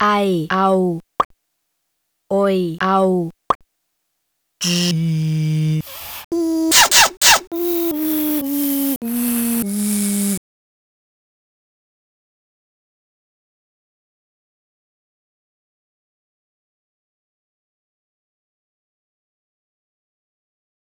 phonetic typewriter ai au beat
Ein großartiges kleines Intro, das die Möglichkeiten von phont mit minimalistischer Klarheit auslotet. Es ist schuld daran, dass man sich den nachfolgenden Ausklang noch nicht so rasch gewünscht hätte, auch wenn sich die Ausdrucksmittel so recht deutlich gegenüberstehen - musikalischer Ausdruck im Intro, lautlicher Ausdruck im Schlußteil.